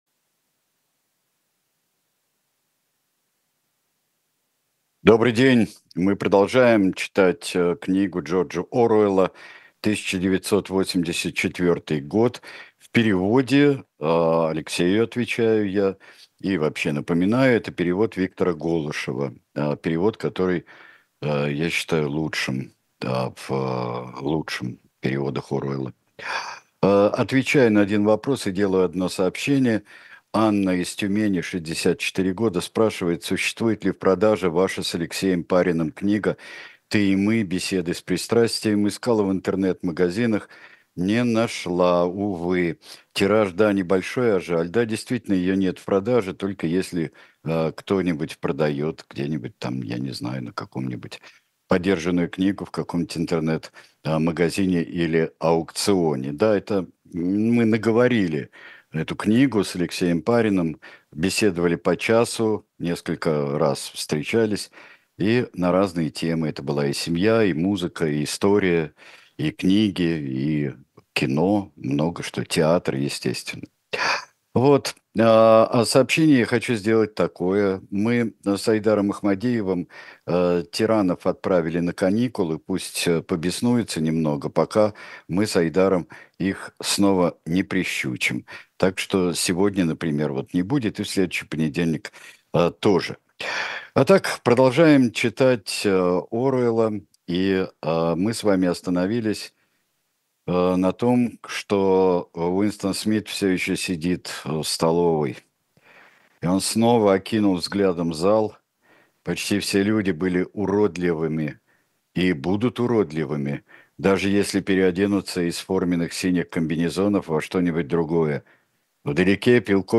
Читает Сергей Бунтман.